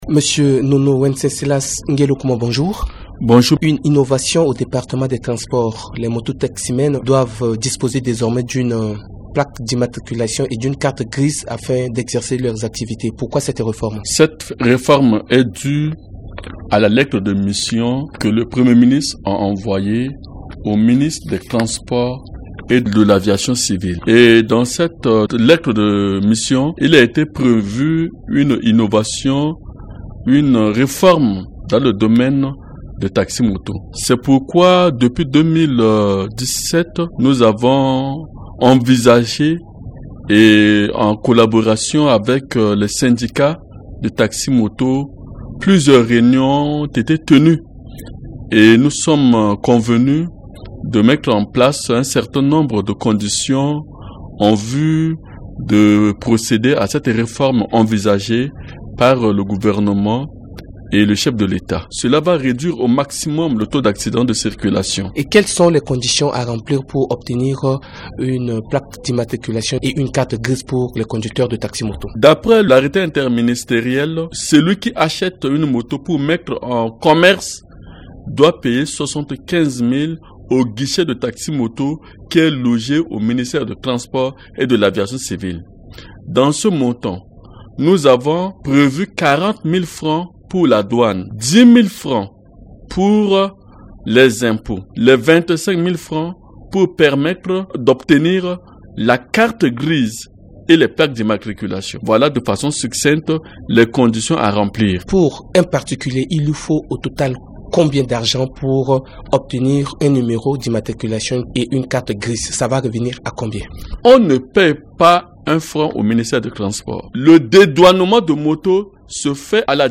Invité DG Transport NGUELEKOUMON Fr .MP3